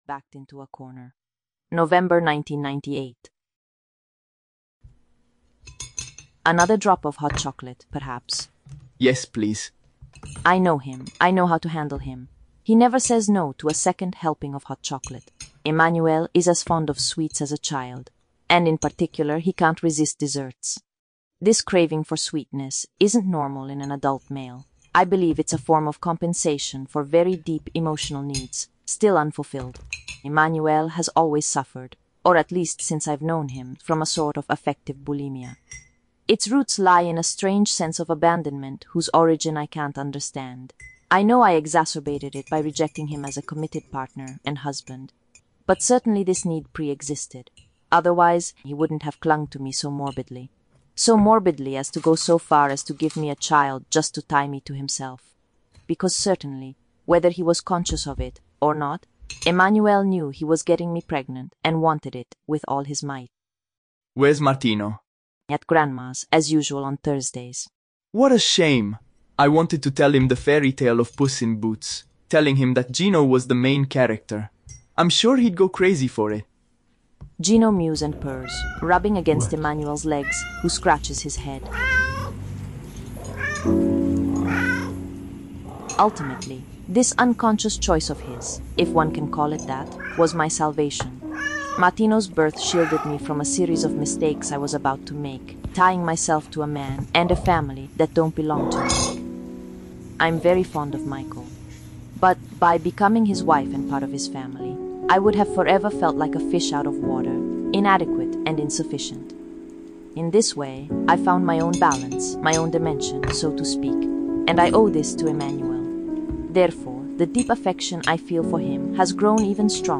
Podcast Novel